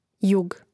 jȕg jug